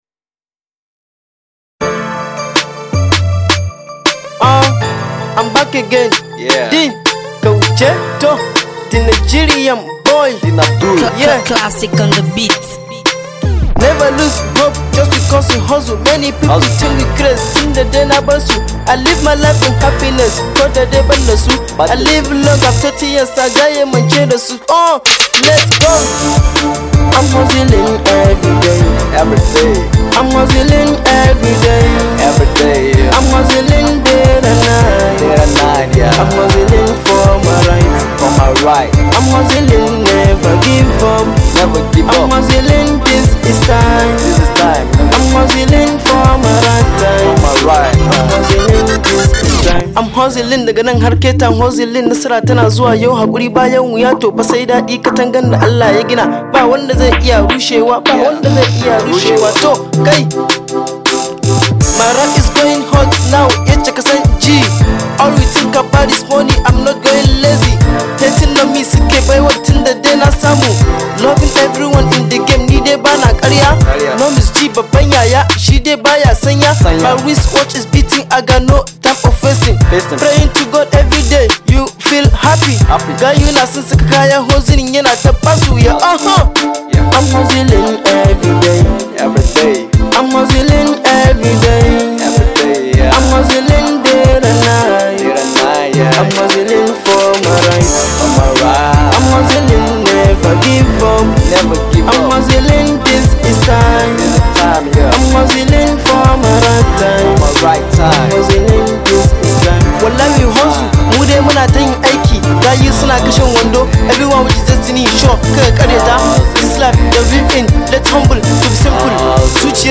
Hausa rap
motivational Hip Hop track